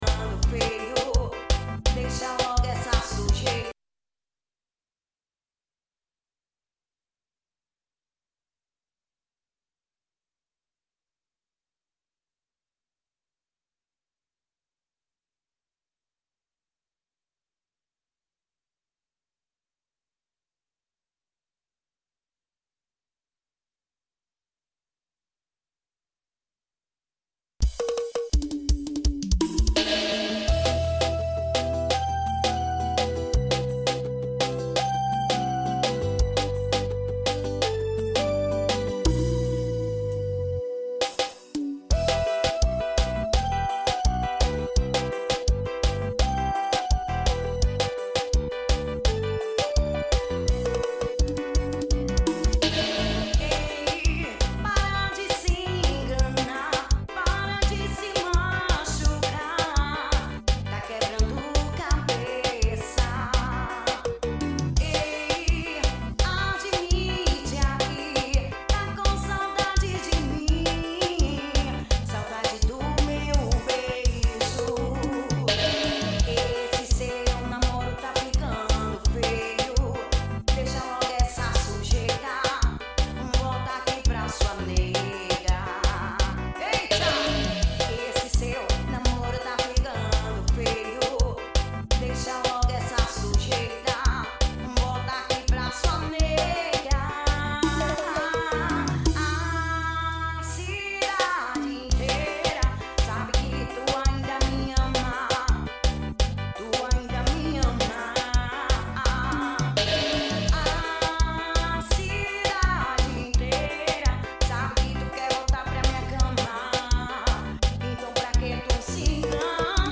AO VIVO SHOW.